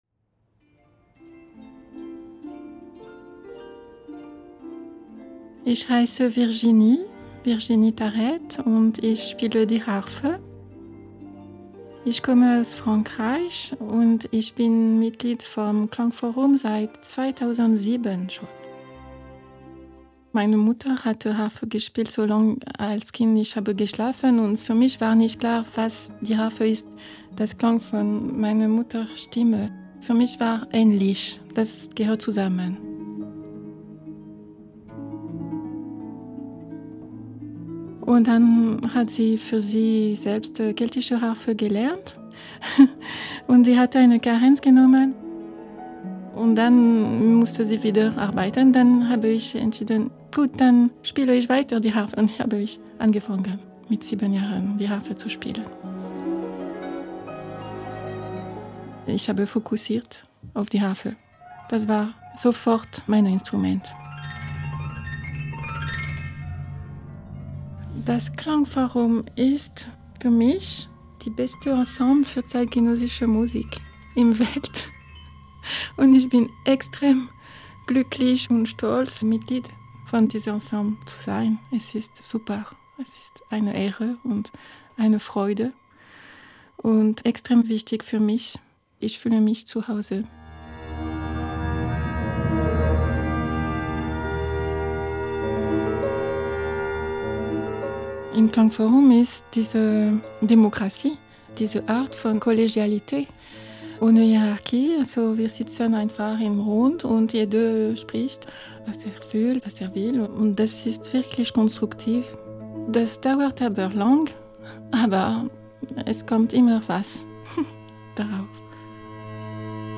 Harfe